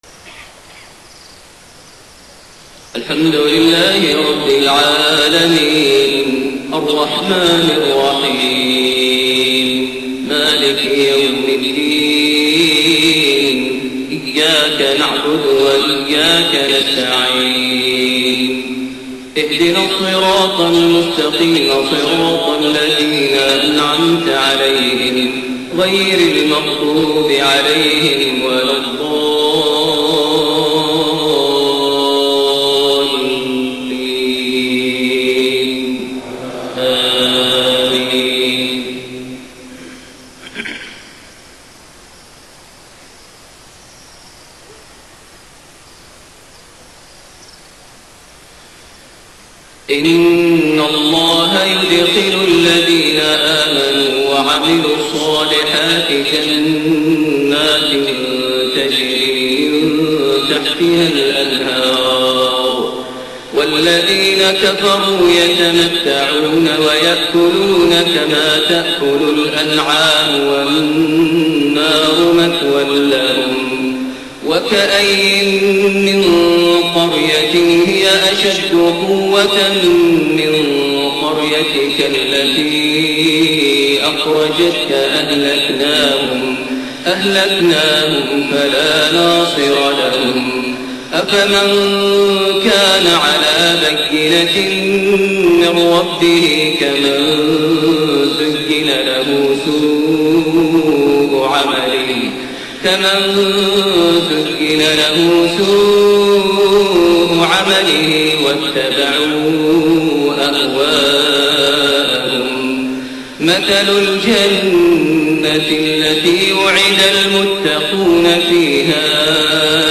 صلاة المغرب 4-6-1432 | من سورة محمد 12-19 > 1432 هـ > الفروض - تلاوات ماهر المعيقلي